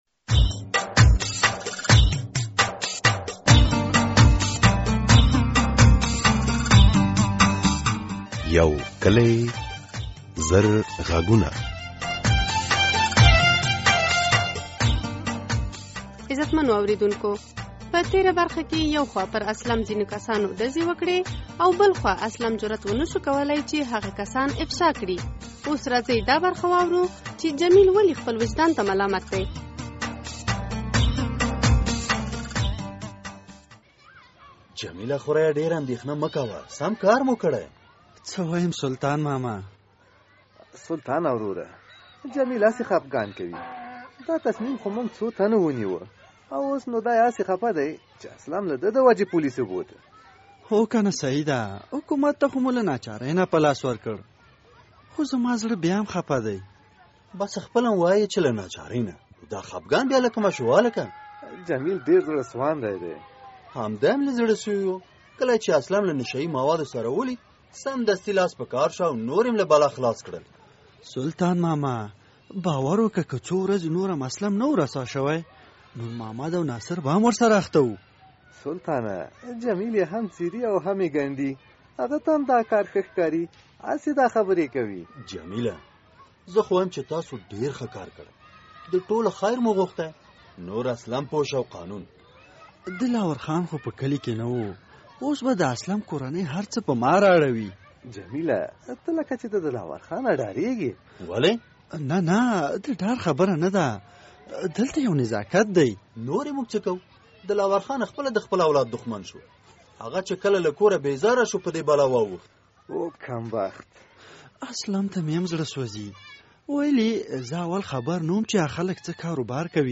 د یو کلي زر غږونو ډرامې ۱۴۰مه برخه
د یو کلي زر غږونو ډرامې لړۍ تاسې هره دوشنبه د ماسپښین په ۲:۳۰ بجو له ازادي راډيو څخه اورېدلای شئ.